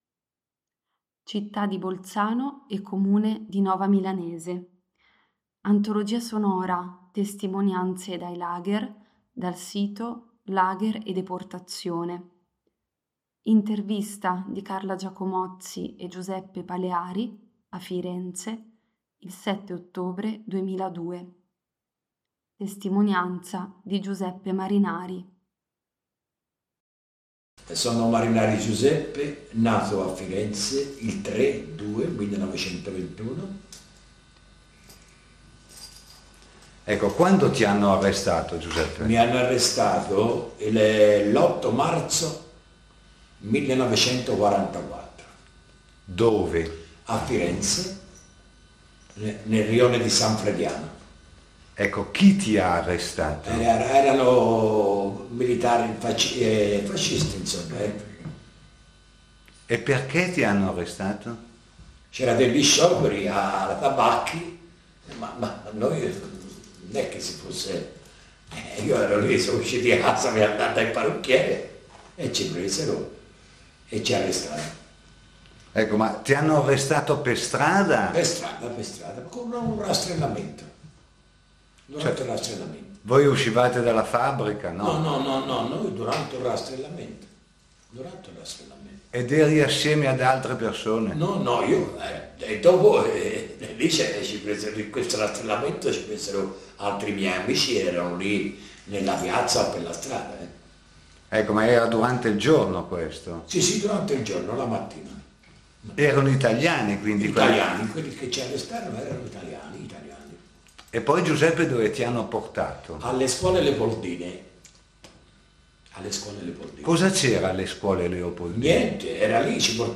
Intervista del 07/10/2002, a Firenze